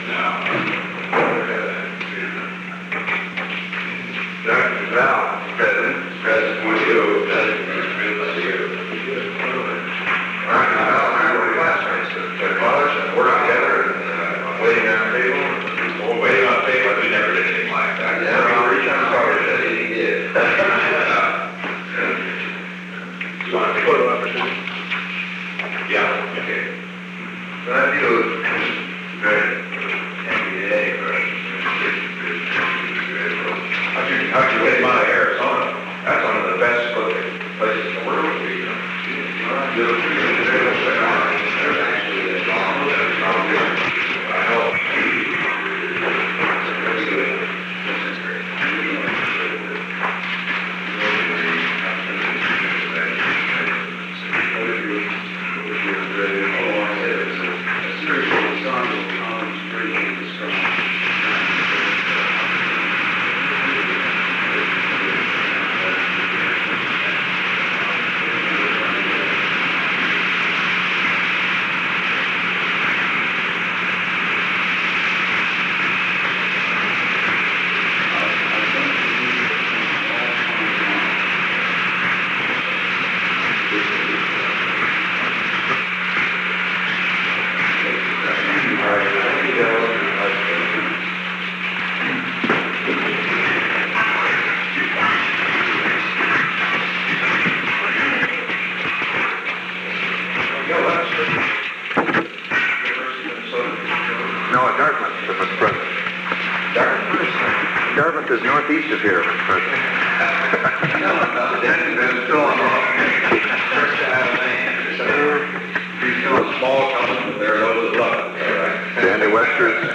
Recording Device: Oval Office
The Oval Office taping system captured this recording, which is known as Conversation 498-003 of the White House Tapes.
Location: Oval Office